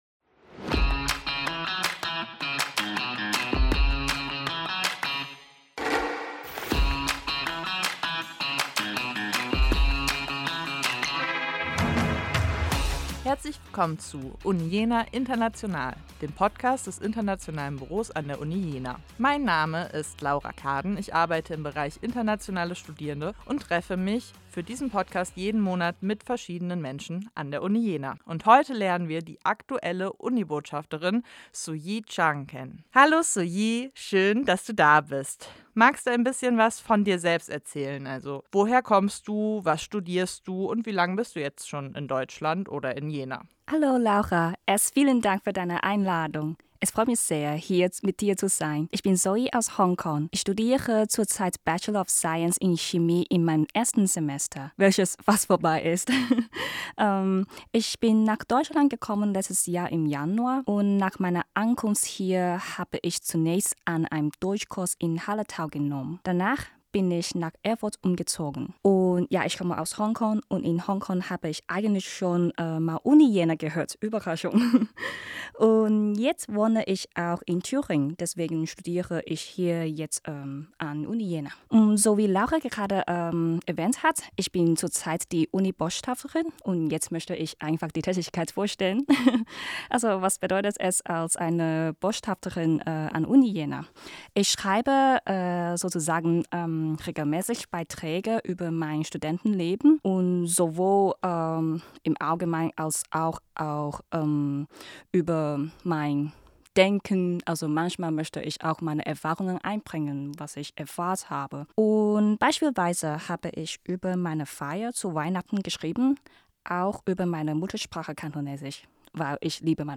Gemeinsam mit Studierenden, Alumni, Mitarbeitenden und Gästen besprechen wir spannende Themen rund um das Studium an der Friedrich-Schiller-Universität Jena.